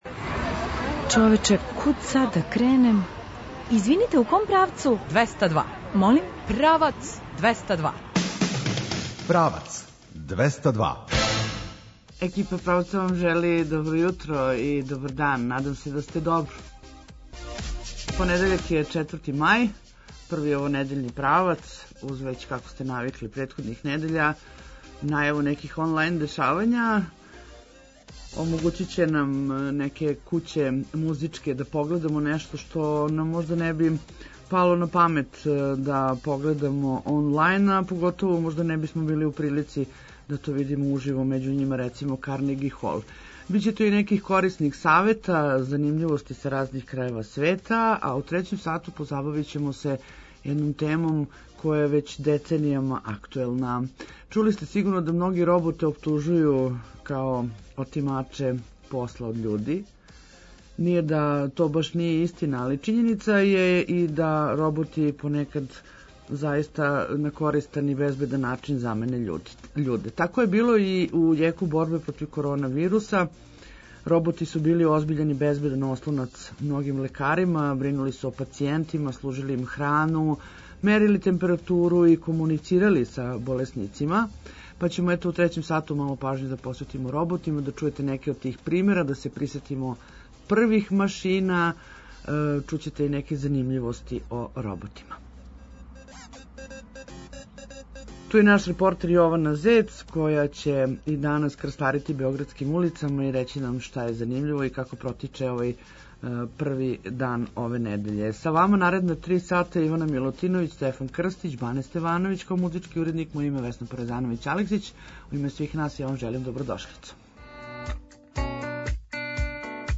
Очекују вас и бројне друге занимљивости, сервисне информације и извештај нашег репортера.